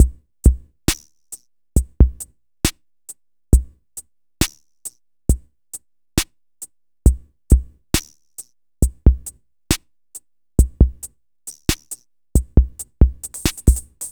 34c-drm-68.aif